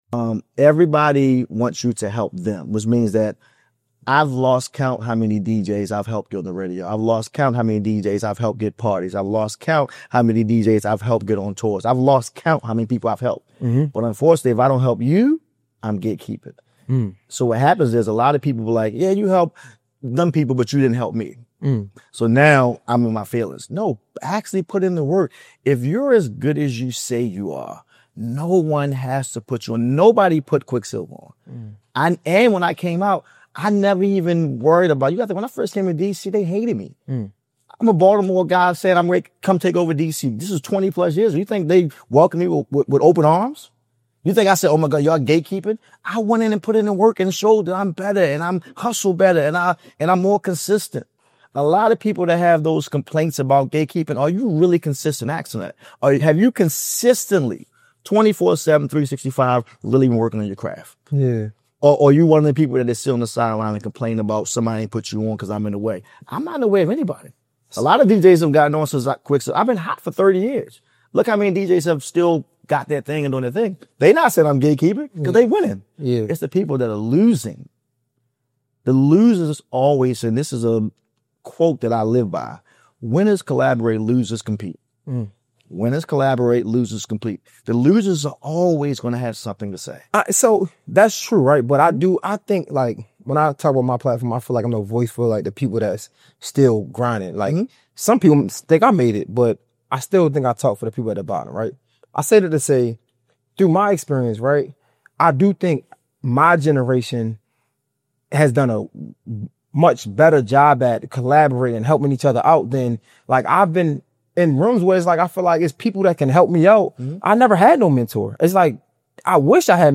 Comedy Interviews